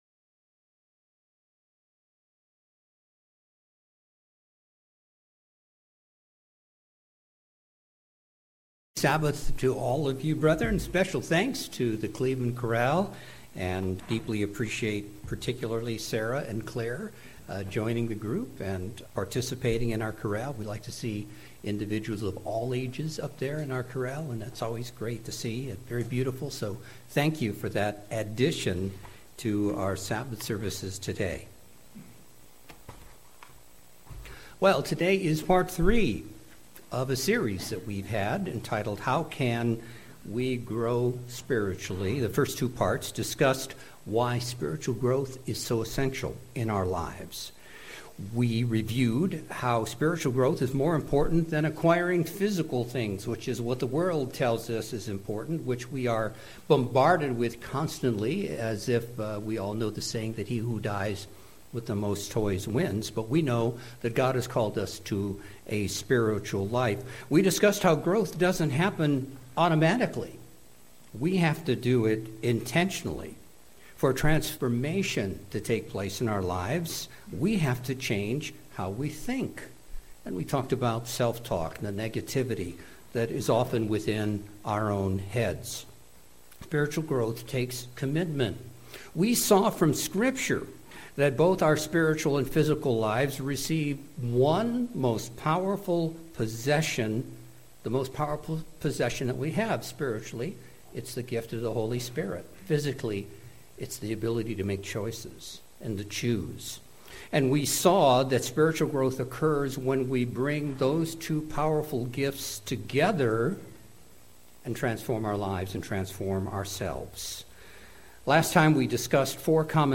Sermon - How Can We Grow Spiritually? - Part 3